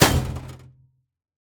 Minecraft Version Minecraft Version 1.21.5 Latest Release | Latest Snapshot 1.21.5 / assets / minecraft / sounds / block / vault / break4.ogg Compare With Compare With Latest Release | Latest Snapshot
break4.ogg